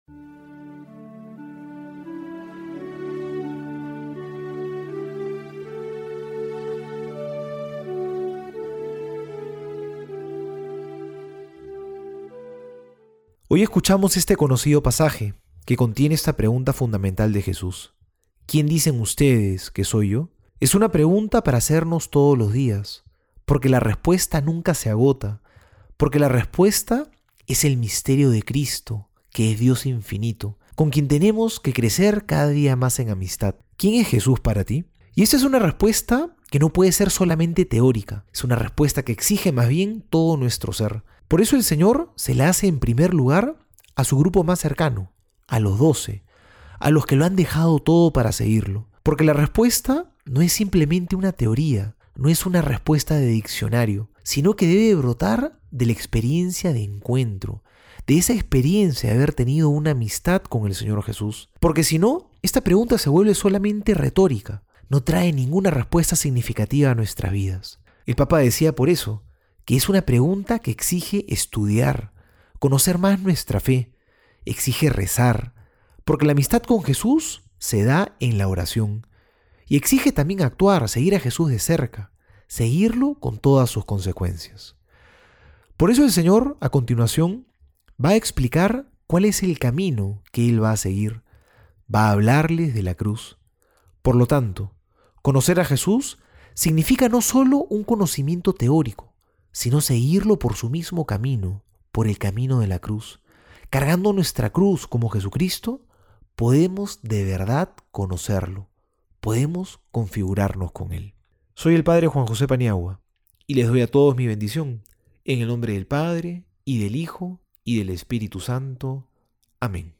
Homilía para hoy: